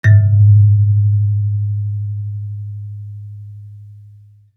kalimba_bass-G#1-pp.wav